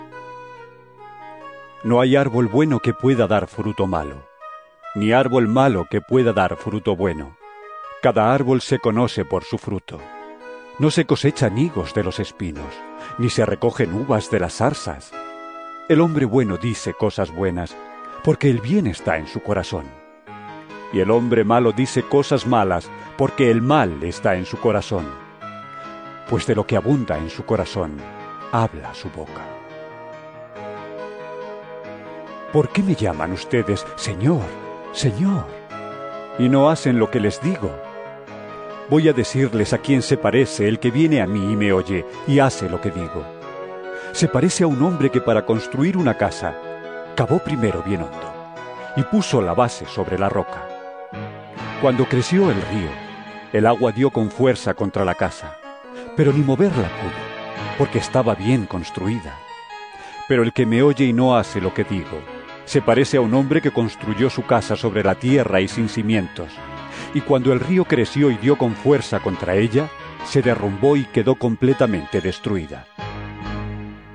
Lc 6 43-49 EVANGELIO EN AUDIO